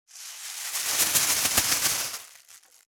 598ゴミ袋,スーパーの袋,袋,買い出しの音,ゴミ出しの音,袋を運ぶ音,
効果音